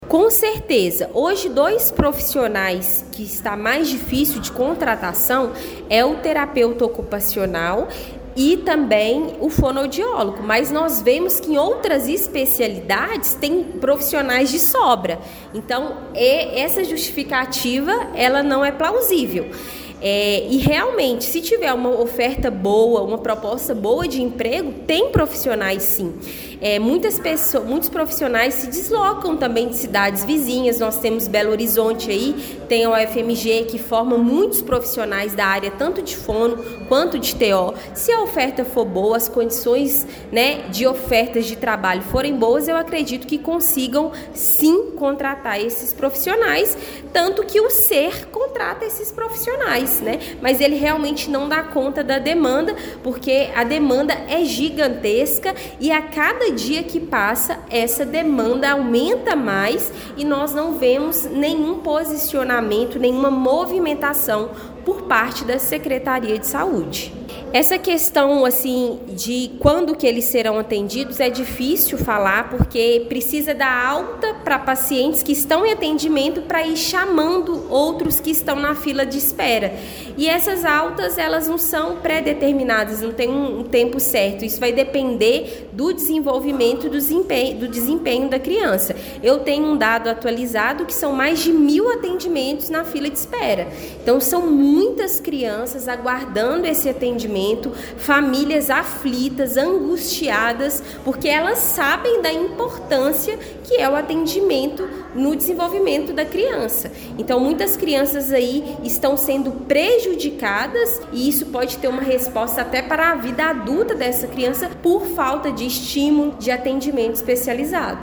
Durante a reunião da Câmara Municipal, realizada ontem, 2 de dezembro, a vereadora Camila Gonçalves de Araújo fez um pronunciamento contundente sobre a longa espera enfrentada por crianças que necessitam de atendimento com fonoaudiólogos e terapeutas ocupacionais.